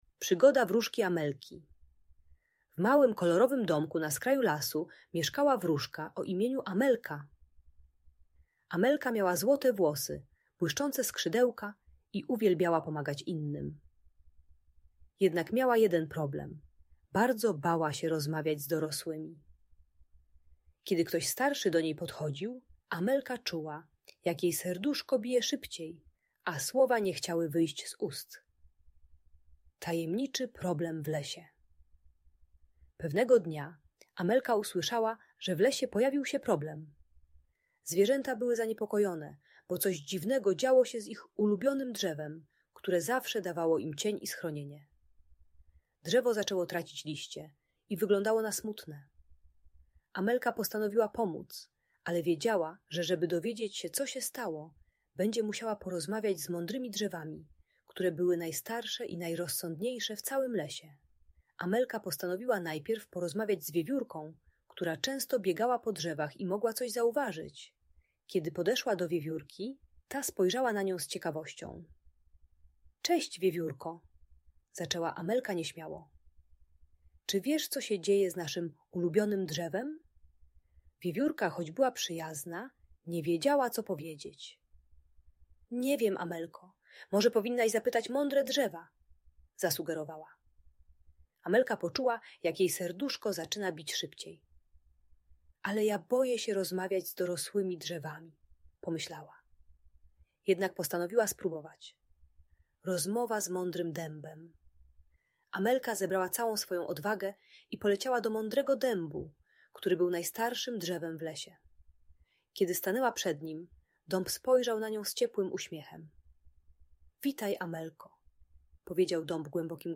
Przygoda Wróżki Amelki - Audiobajka